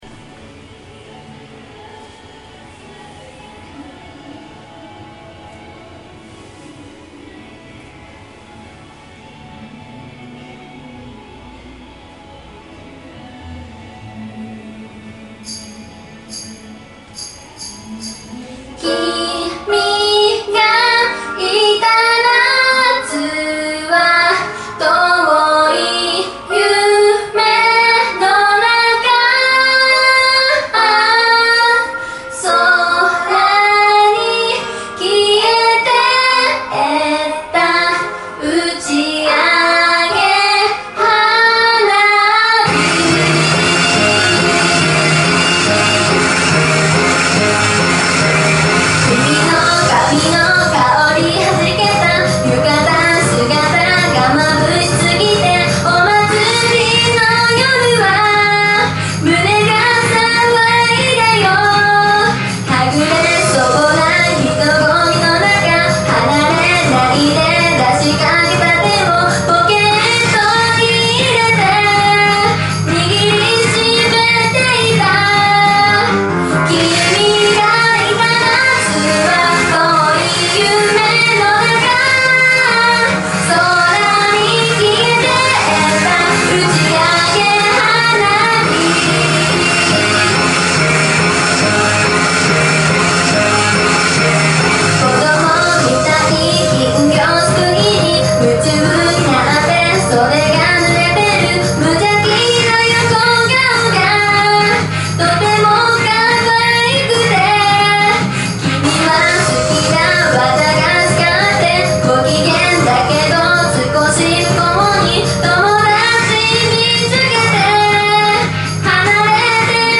オフマイク／音楽収録用マイクロフォン使用